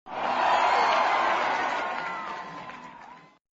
us_cheer.mp3